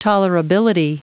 Prononciation du mot tolerability en anglais (fichier audio)
Prononciation du mot : tolerability